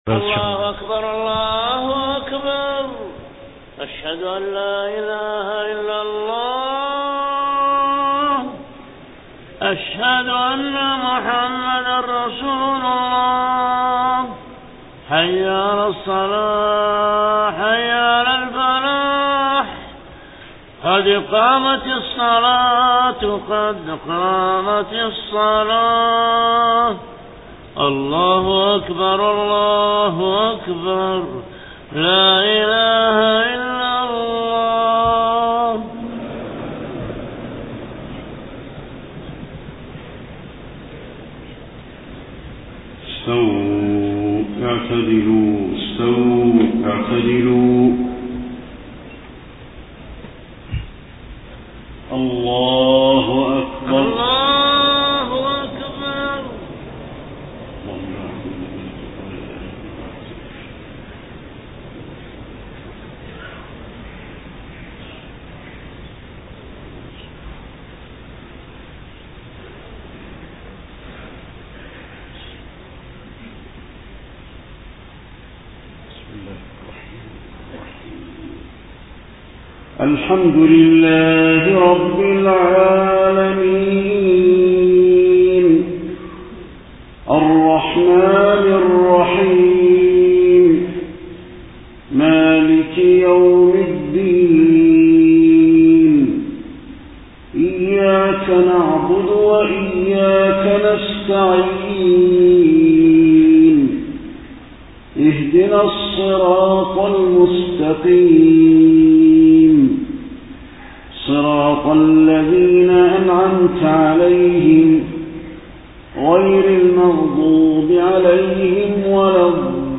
صلاة العشاء 26 صفر 1431هـ من سورة الفرقان 25-40 > 1431 🕌 > الفروض - تلاوات الحرمين